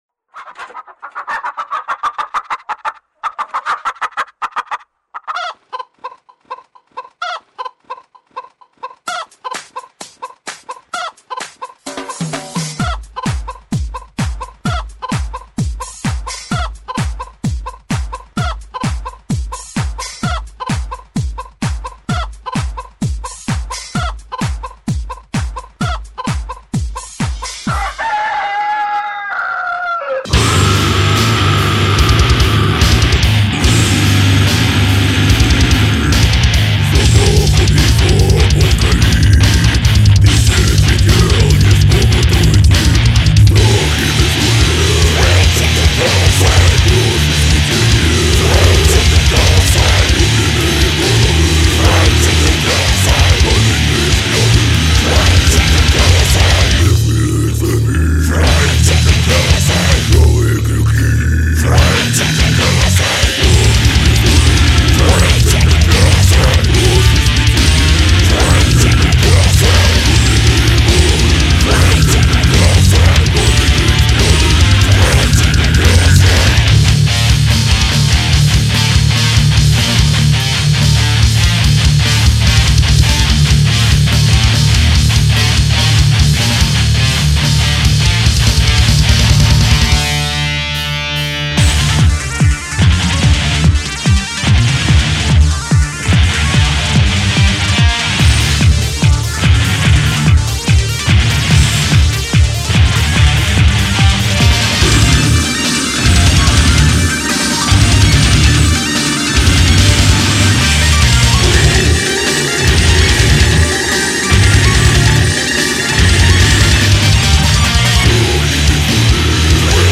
У нас же — чисто эмоциональная.
там ещё куры вначале кудахчут
Чем ваша музыка отличается от других команд, играющих grind?